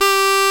CHANTER G1.wav